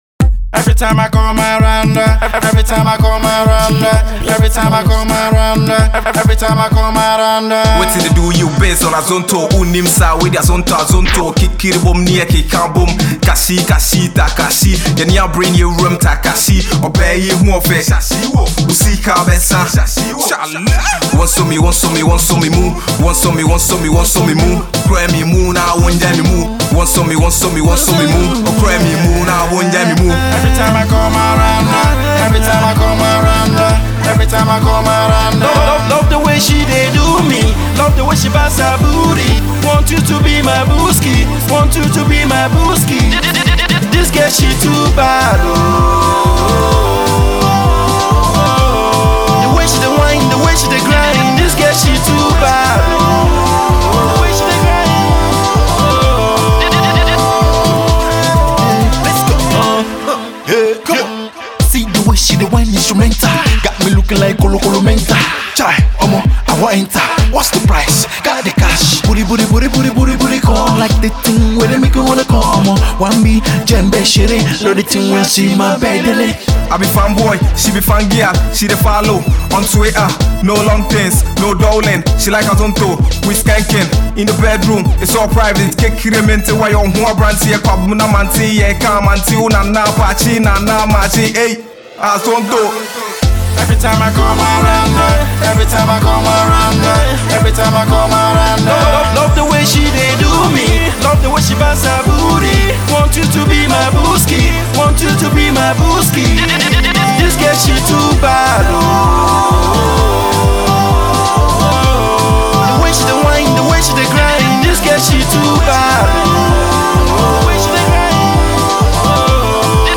infused with Grime and funky Afrobeat